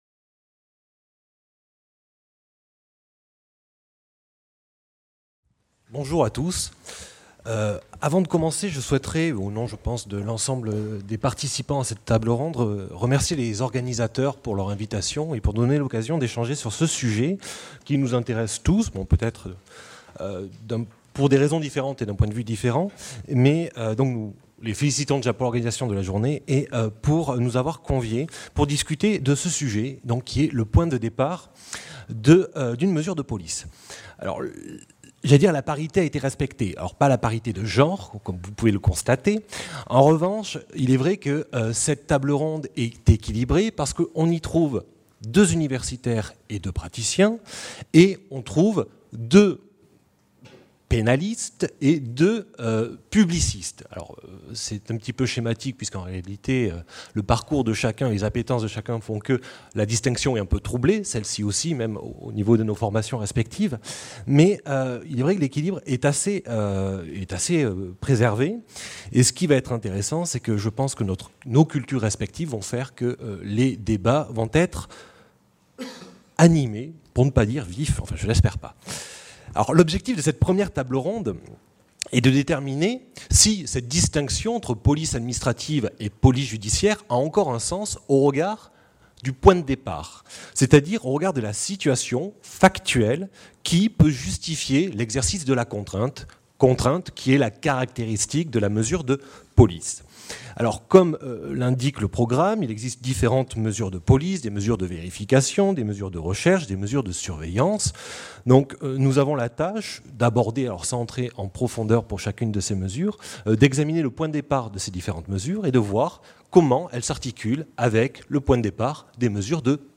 Table ronde n°1: Le point de départ d'une mesure de police.